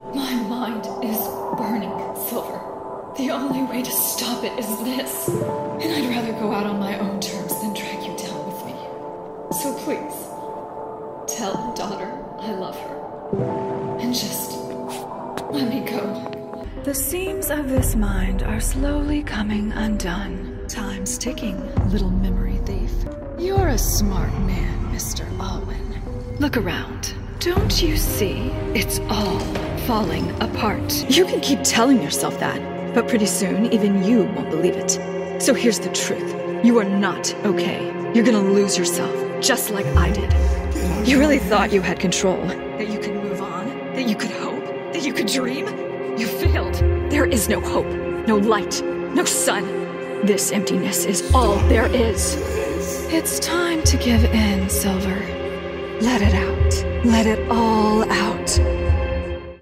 Emotional